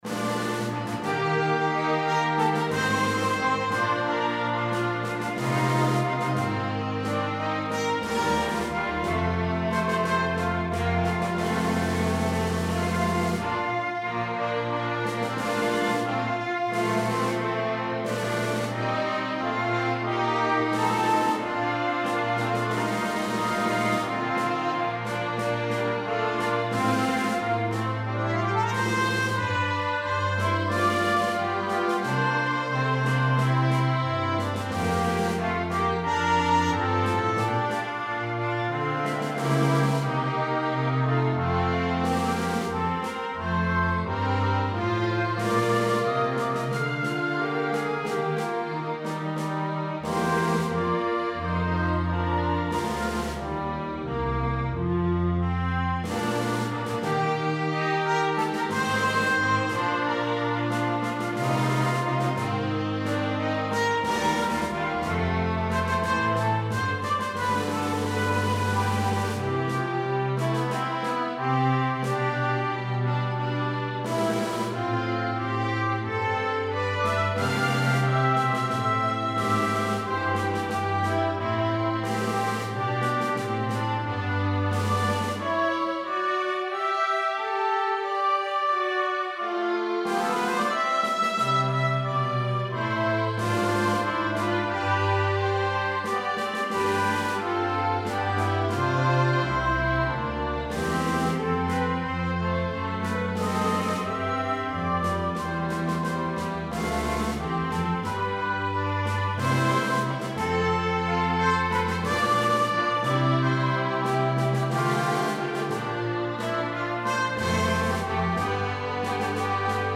This is a strong and stately setting
Arranged in 4 parts, fully orchestrated.